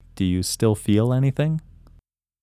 IN – First Way – English Male 23
IN-1-English-Male-23.mp3